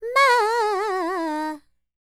QAWALLI 16.wav